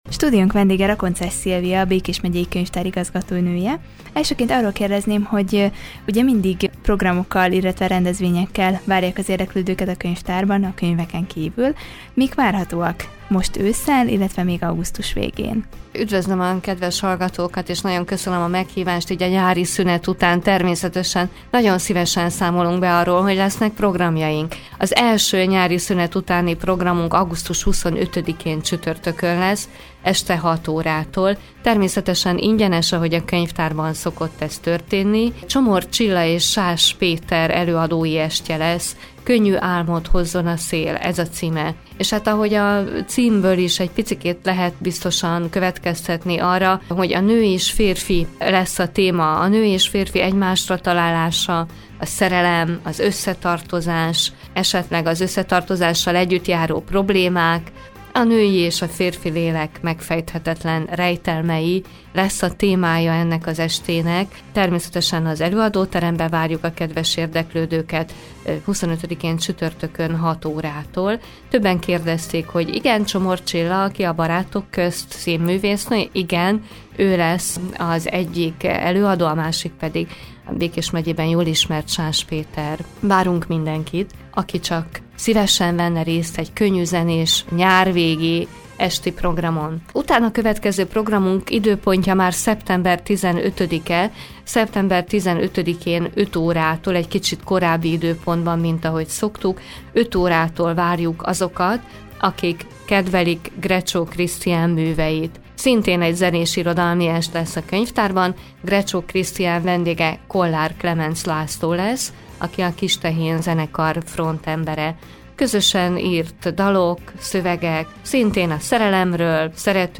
Vele beszélgetett tudósítónk az induló e-könyv kölcsönzésről valamint a nyár végi és őszi programokról.